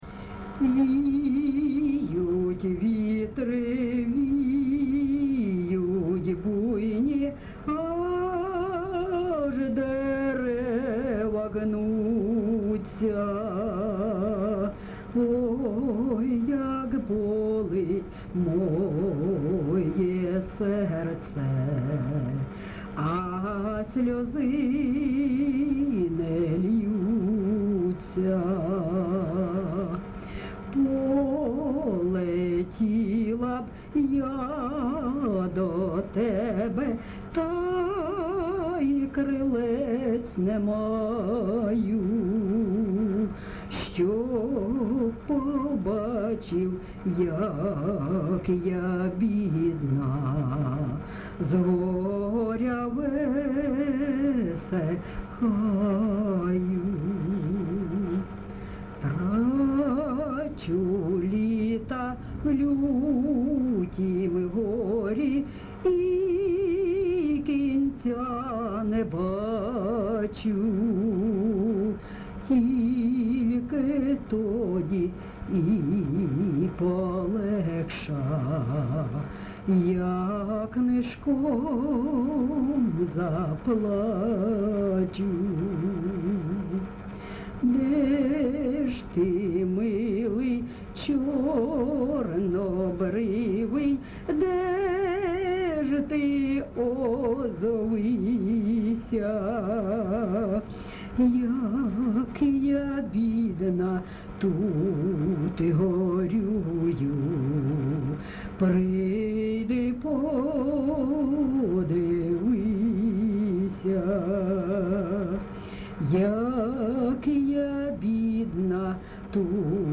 ЖанрПісні літературного походження
Місце записус. Лозовівка, Старобільський район, Луганська обл., Україна, Слобожанщина